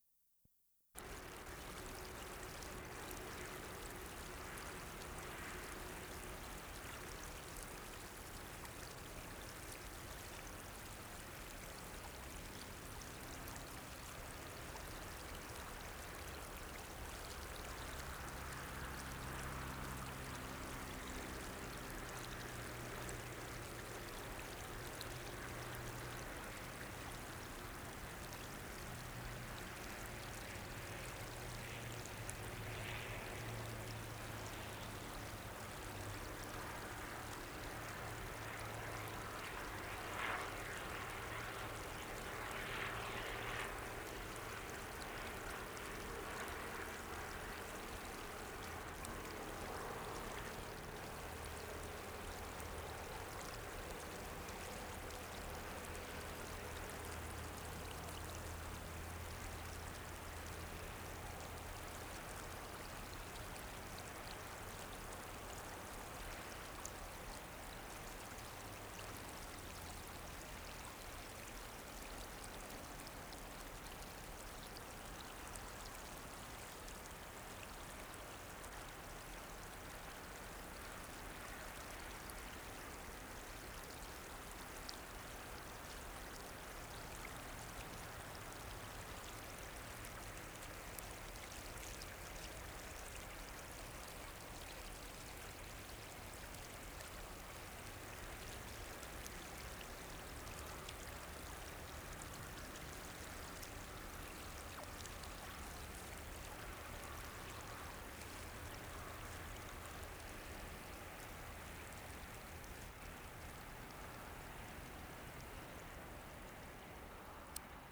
WORLD SOUNDSCAPE PROJECT TAPE LIBRARY
HUME PARK, NEW WESTMINSTER 2'00"
3-5. Pleasant running stream with a dense but distant background of traffic.